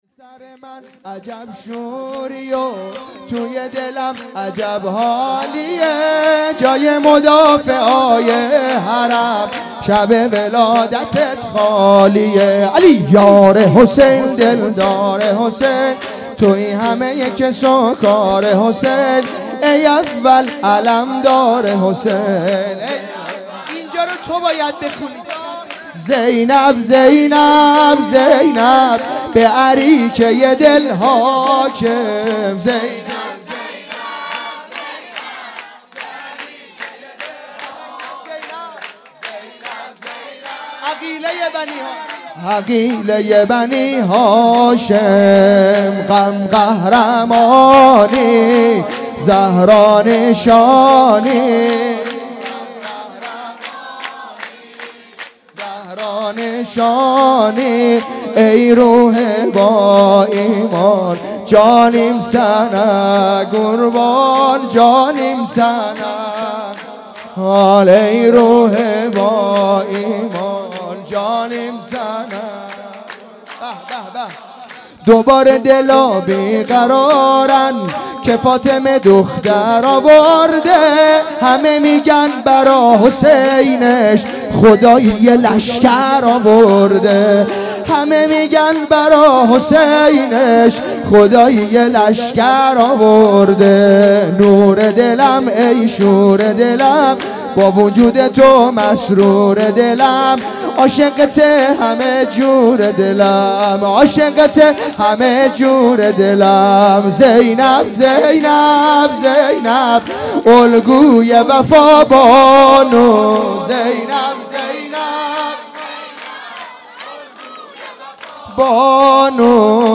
جشن ولادت حضرت زینب سلام الله علیها